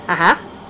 Ah Ha (cartoon) Sound Effect
Download a high-quality ah ha (cartoon) sound effect.
ah-ha-cartoon-2.mp3